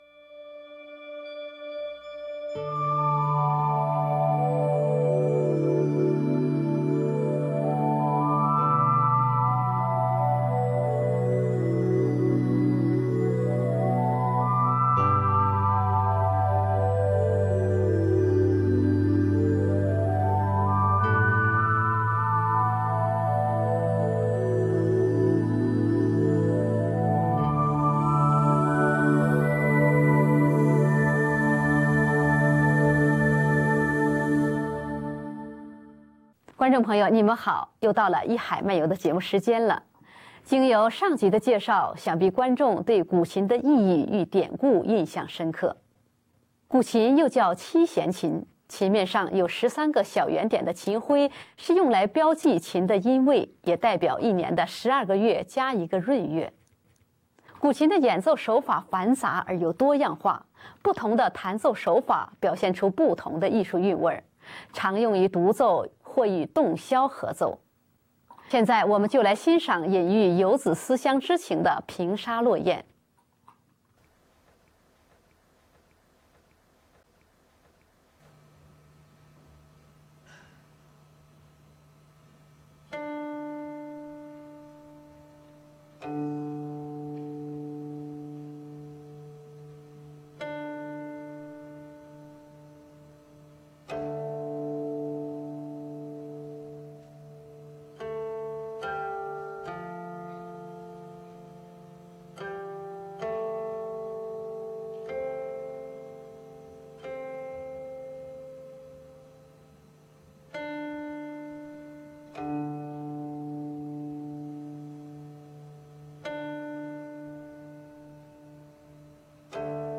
藝海漫遊第017集:山水清音古琴音樂會下集 請欣賞隱喻遊子思鄉之情的「平沙落雁」。靜心品味指法輕捷的「石上流泉」。